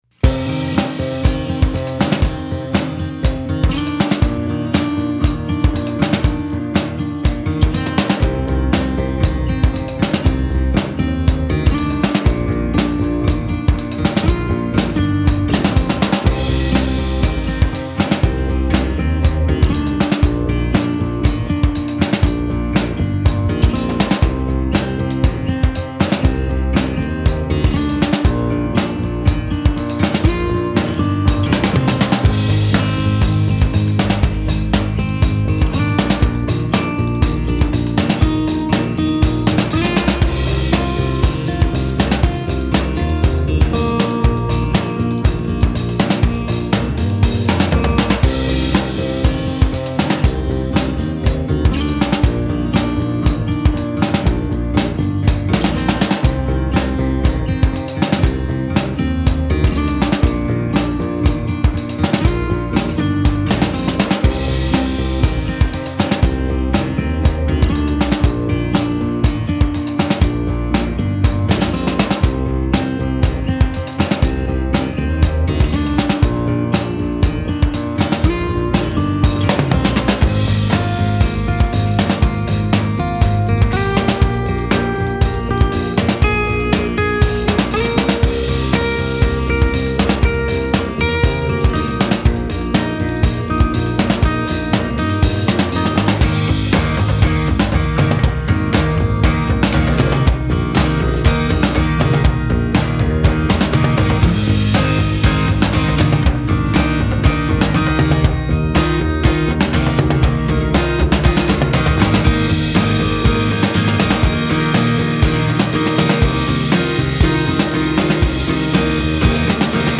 Wie ein Chamäleon passen wir uns den aktuellen Gegebenheiten an und haben von März bis Mai eine virtuelle Jamsession organisiert.
Im Anschluss haben wir das Ergebnis professionell vertont und ein Foto der Jammer:in erstellt.
Instrumental
Schlagzeug
Akustikgitarre
Bass
HIDEJAM_Instrumental.mp3